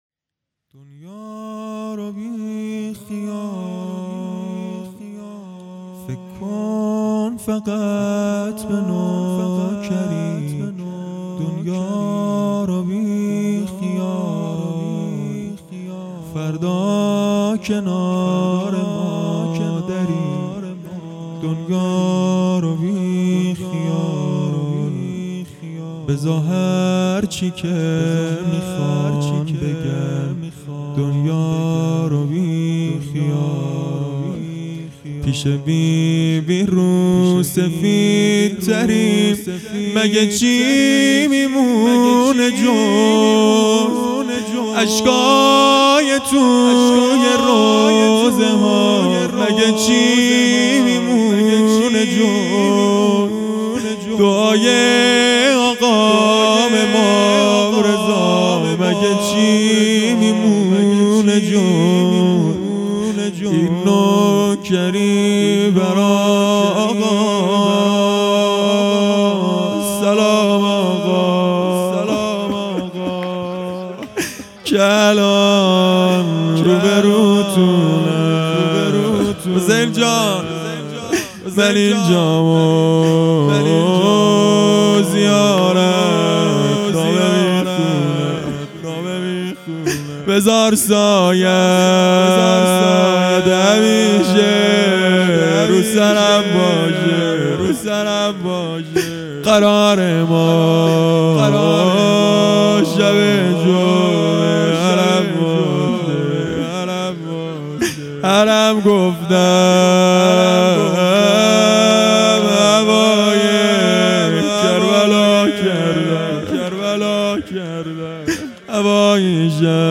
خیمه گاه - هیئت انصارالمهدی(عج) درچه - مناجات پایانی | دنیا رو بیخیال